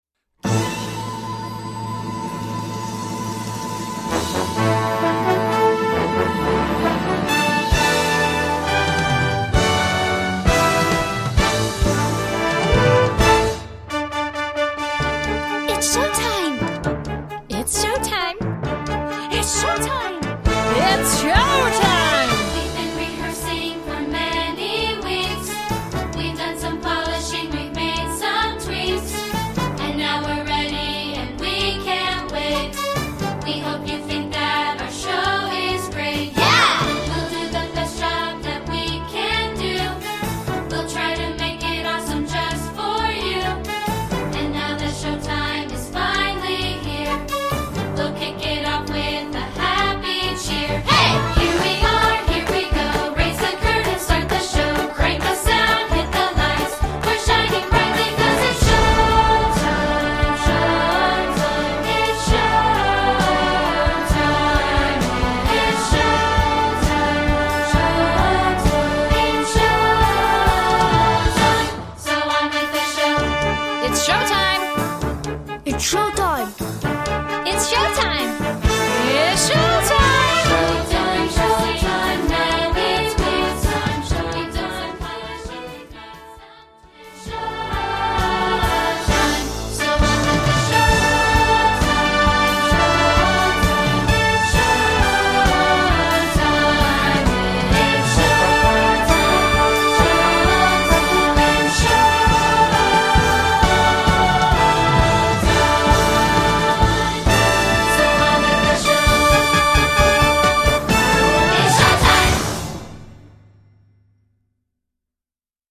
Voicing: Director's Kit